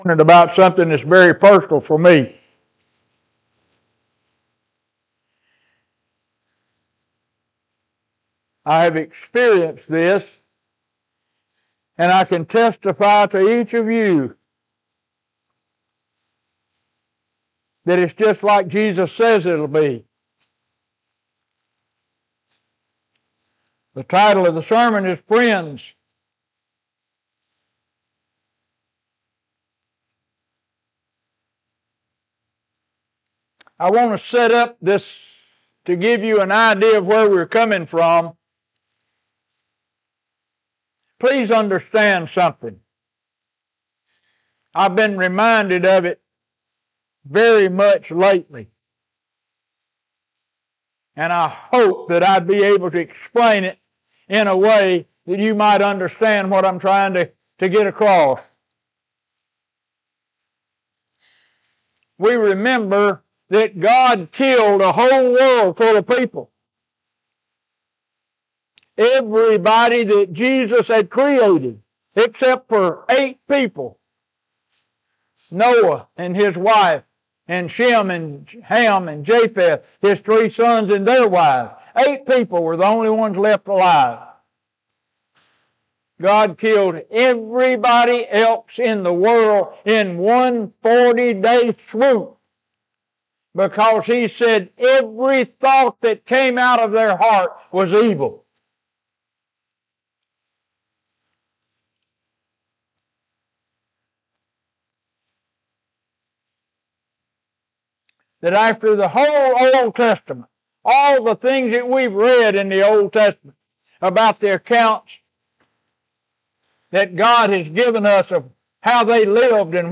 Sermon: Friends